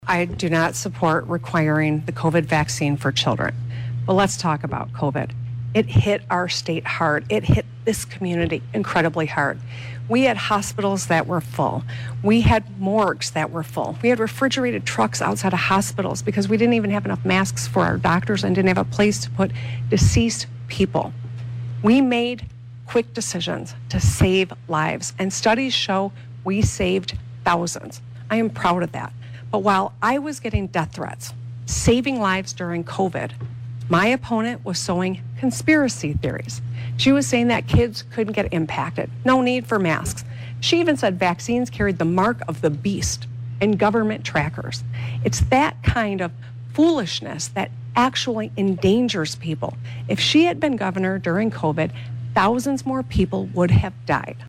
The debate brought the two contenders together for hour-long event at Oakland University.
The incumbent countered.